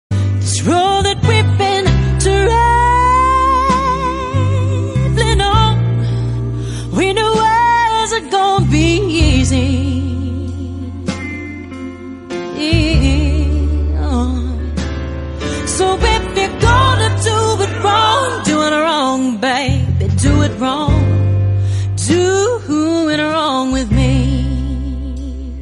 M4R铃声, MP3铃声, 欧美歌曲 37 首发日期：2018-05-14 09:01 星期一